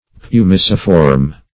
Meaning of pumiciform. pumiciform synonyms, pronunciation, spelling and more from Free Dictionary.